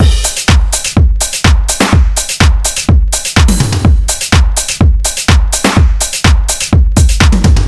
电子鼓
描述：适用于所有类型的电子/house音乐。
Tag: 125 bpm House Loops Drum Loops 1.29 MB wav Key : Unknown